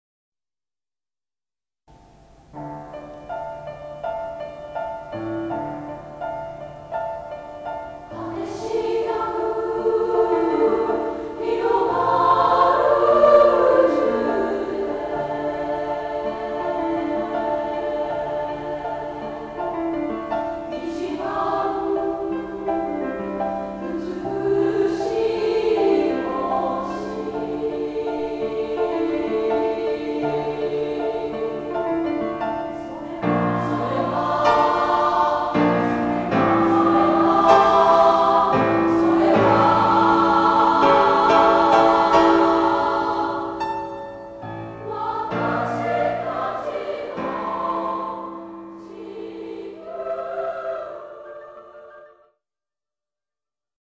楽譜販売中：女声合唱組曲「地雷ではなくをください